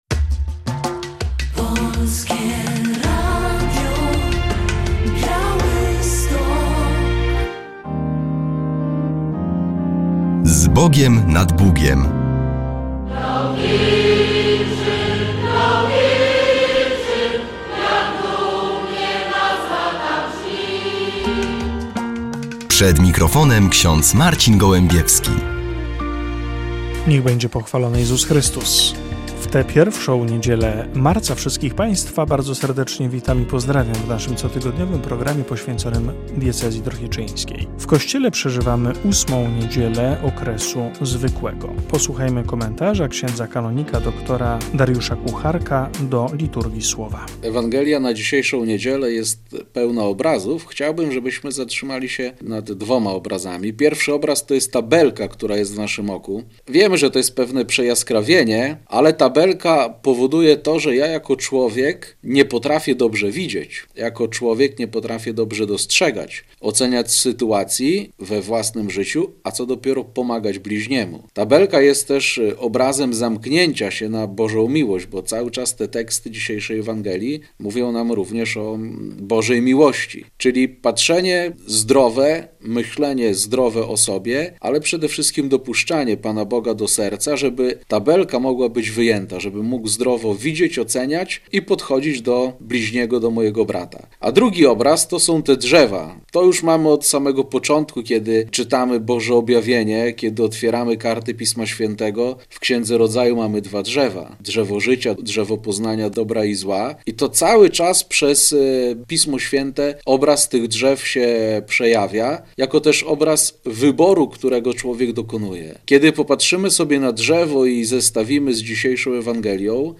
W audycji komentarz